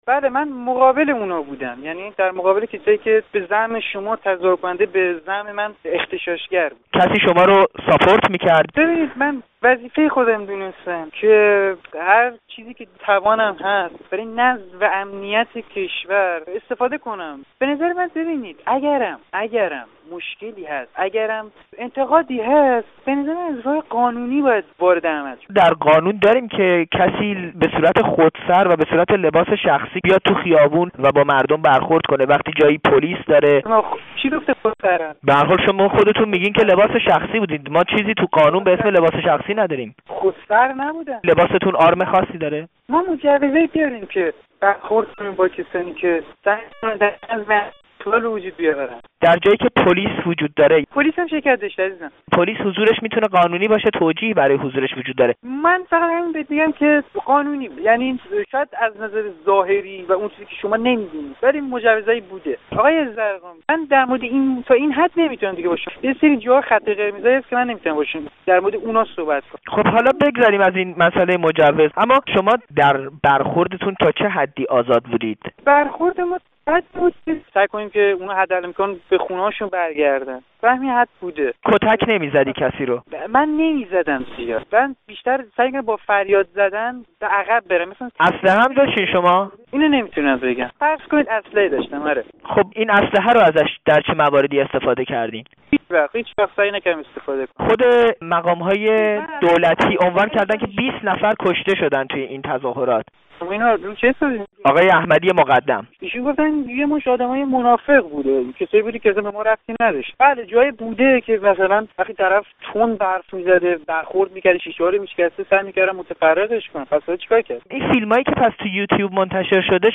گفت‌وگو با یک لباس‌شخصی؛ «مجوز داشتیم»